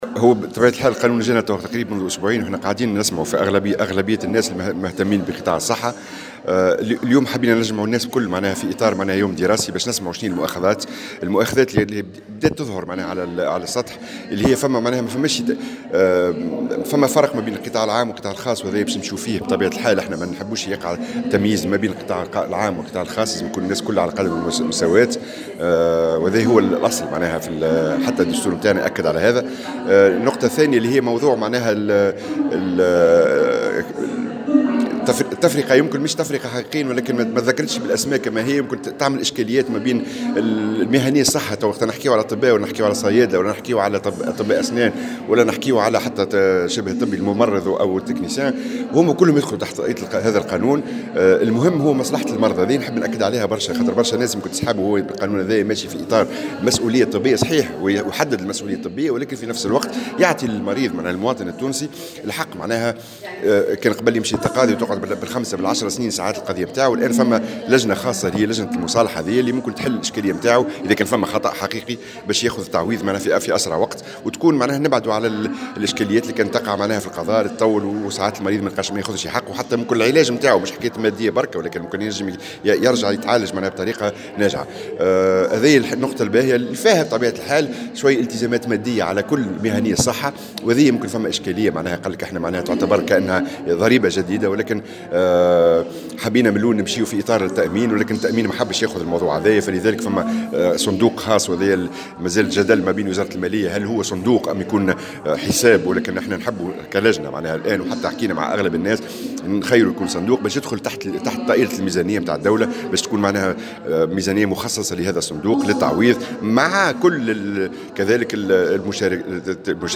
كشف رئيس لجنة الصحة بمجلس نواب الشعب، سهيل العلويني، اليوم الخميس في تصريح لمراسل "الجوهرة أف أم" عن أبرز المؤاخذات بخصوص مشروع قانون يتعلق بالتعويضات عن الأخطاء الطبية.